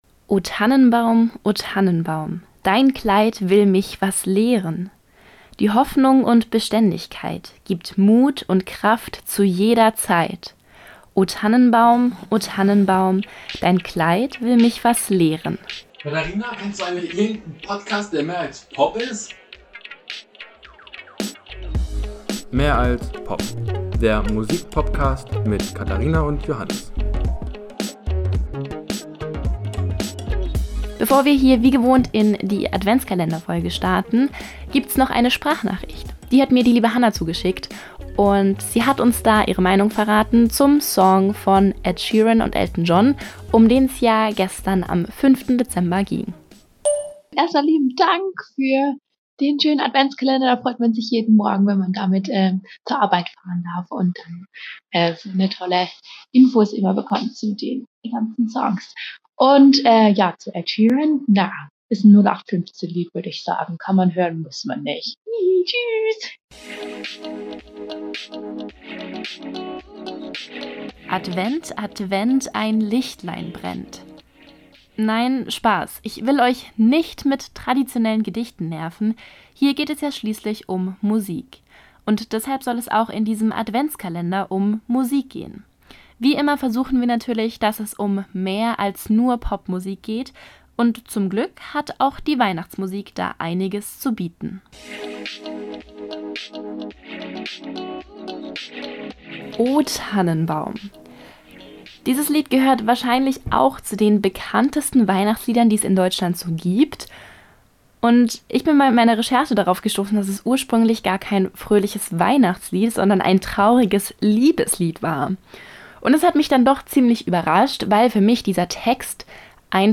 Die Musik für Intro und Outro ist von WatR.